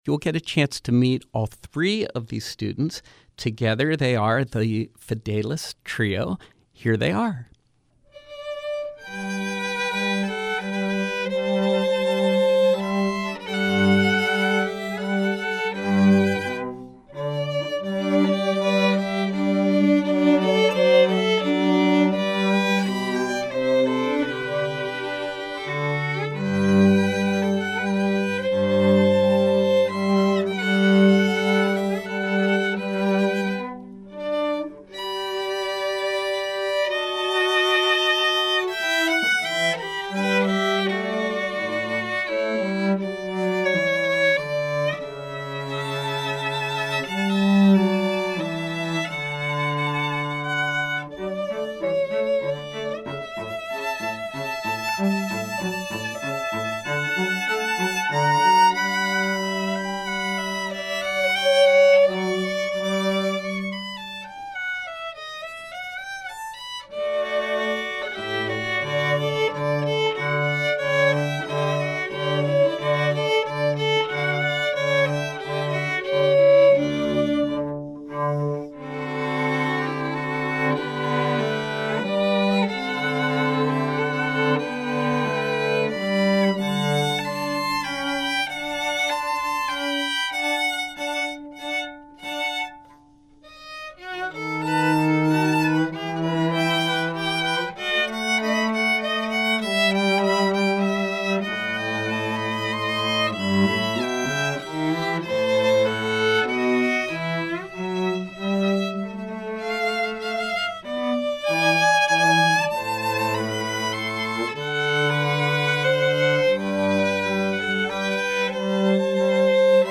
The Fidelis Trio featuring ninth graders
violin
cello